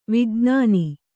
♪ vijṇāni